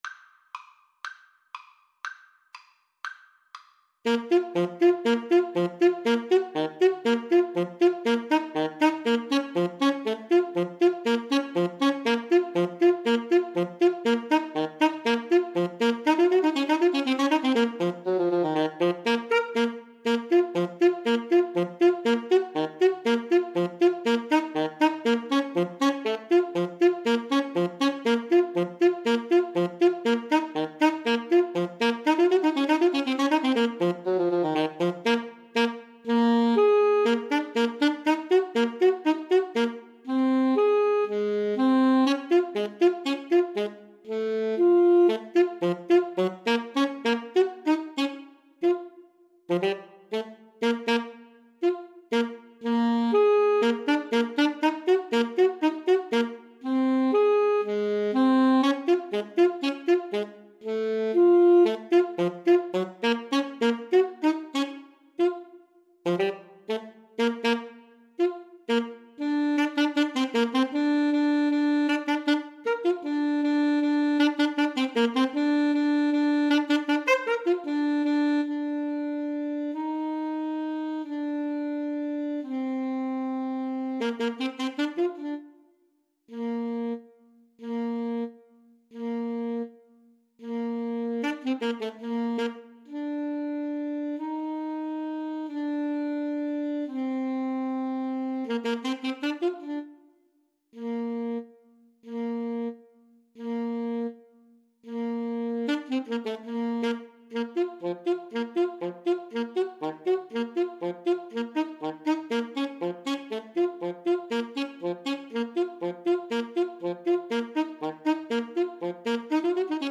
Play (or use space bar on your keyboard) Pause Music Playalong - Player 1 Accompaniment reset tempo print settings full screen
Bb minor (Sounding Pitch) F minor (French Horn in F) (View more Bb minor Music for Alto Saxophone Duet )
2/4 (View more 2/4 Music)
Allegro = 120 (View more music marked Allegro)
Classical (View more Classical Alto Saxophone Duet Music)